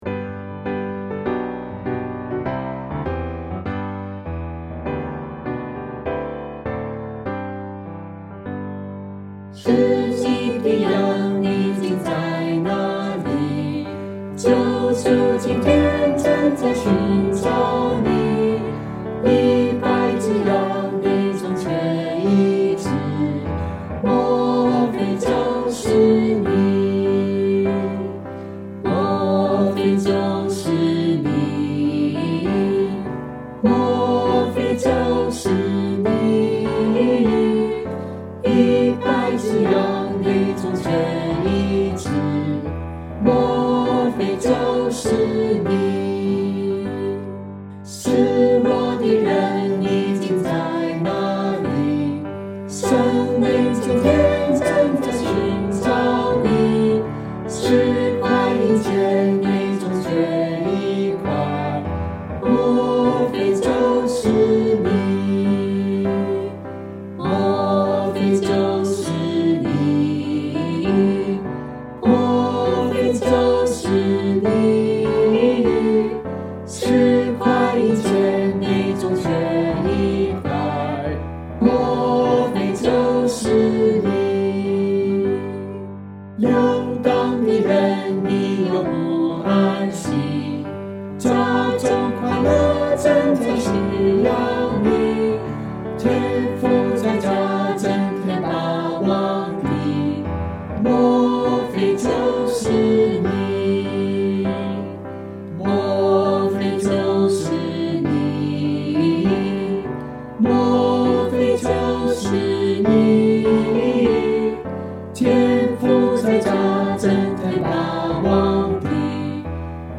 福音
G Major